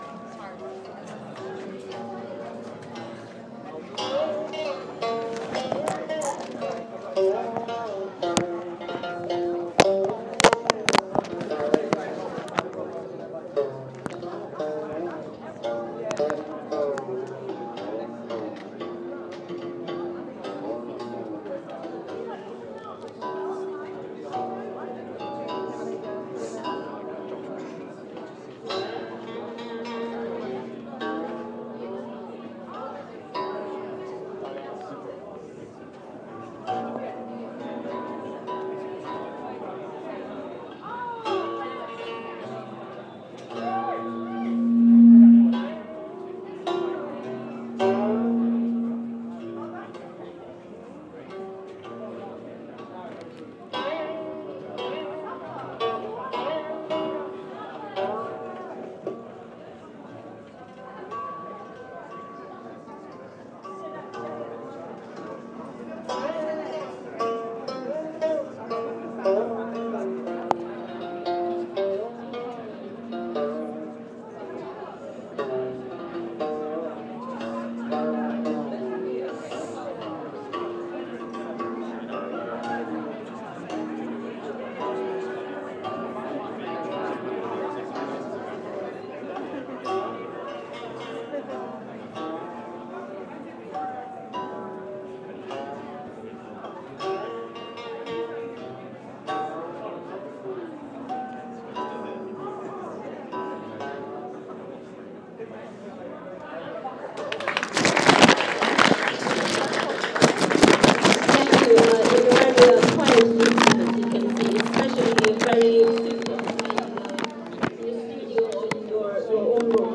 Demo of guchin (Chinese instrument) at SOAS